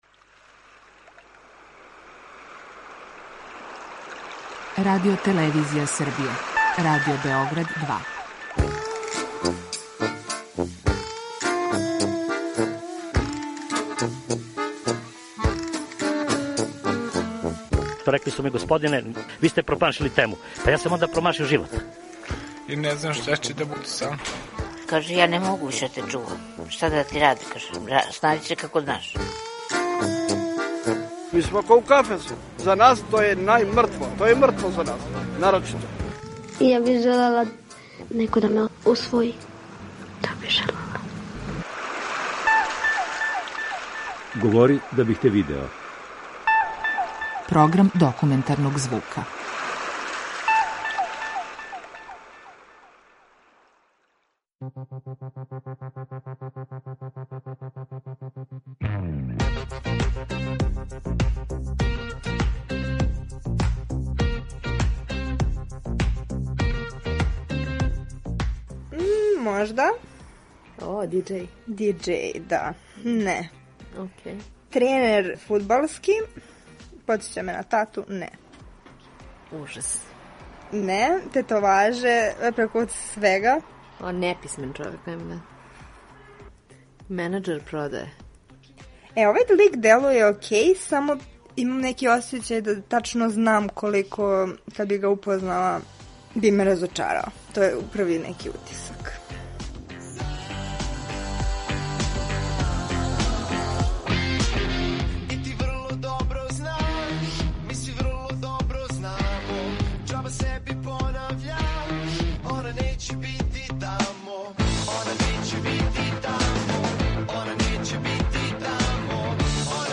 Документарни програм
Такозване „дејтинг" апликације све су заступљеније у свакодневном животу младих данас. Своја искуства са Тиндера, најпопуларније апликације за упознавање у Србији, поделиће са нама неколико њених корисника.